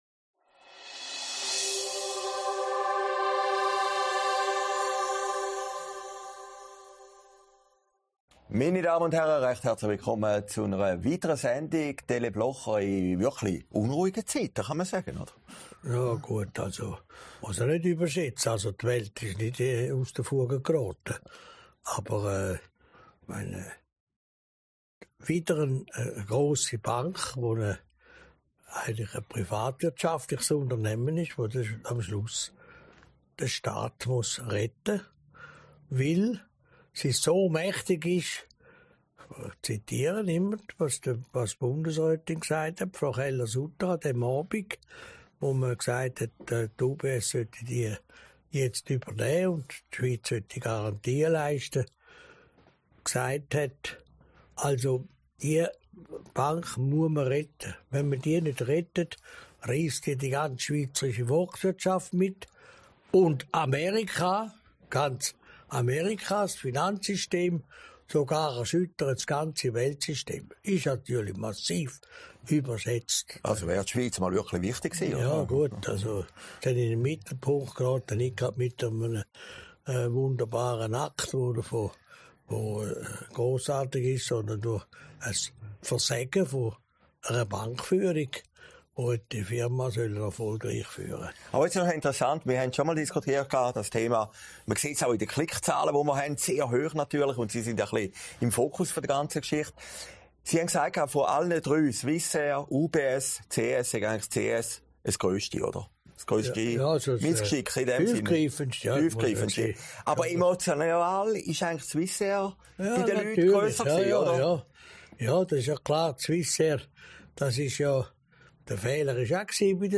Sendung vom 21. April 2023, aufgezeichnet in Herrliberg